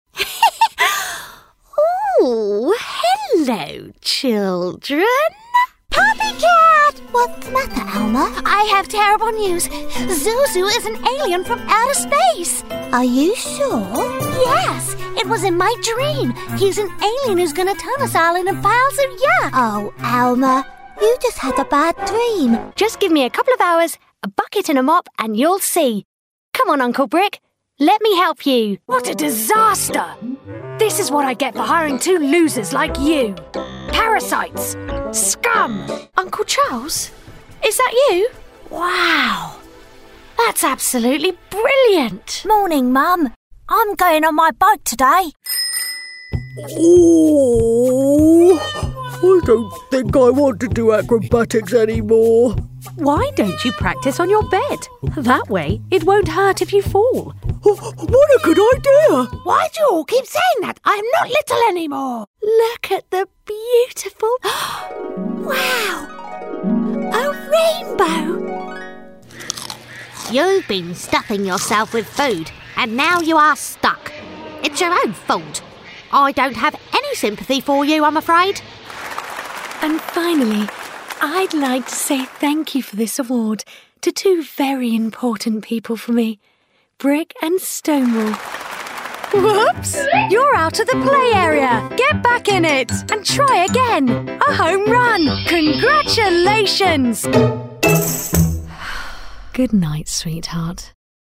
Female
English (British), English (Neutral - Mid Trans Atlantic)
Natural, warm, engaging, inspiring, young, upbeat, fresh, sassy, commercial and with a husky undertone. Characters and kids too!
Main Demo
All our voice actors have professional broadcast quality recording studios.